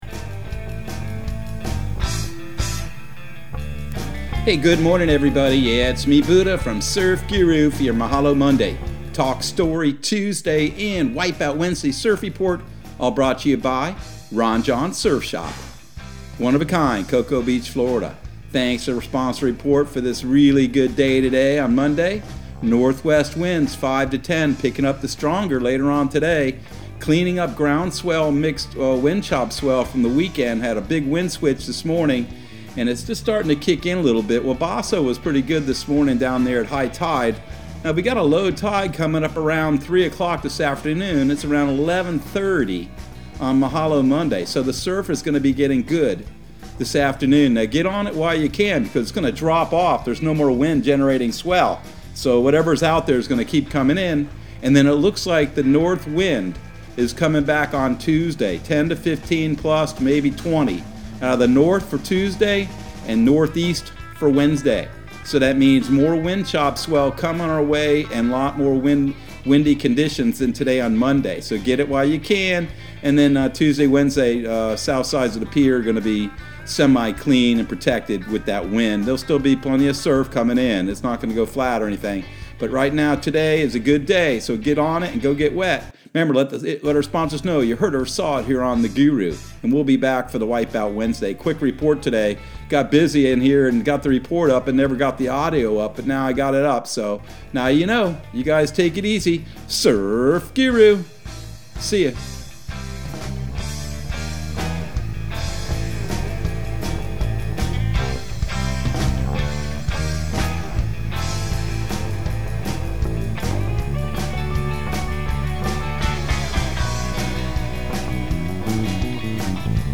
Surf Guru Surf Report and Forecast 11/22/2021 Audio surf report and surf forecast on November 22 for Central Florida and the Southeast.